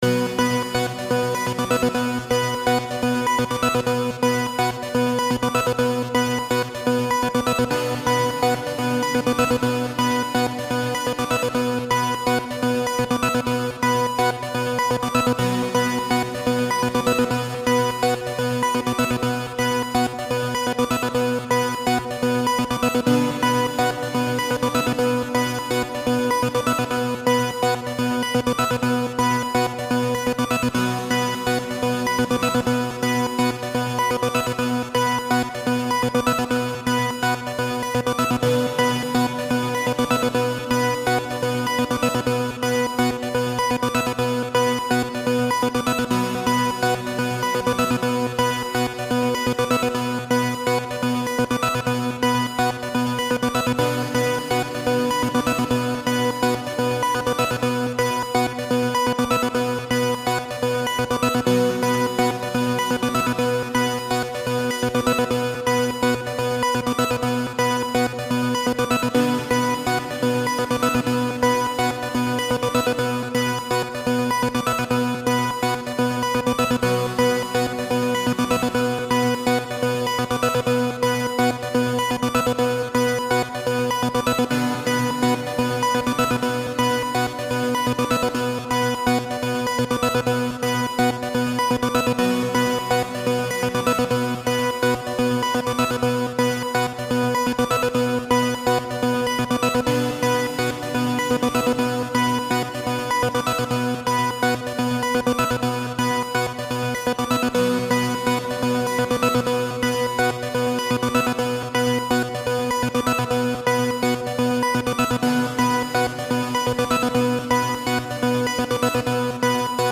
【用途/イメージ】ニュース　メディア　ドキュメント　報道　ナレーション　緊急　事件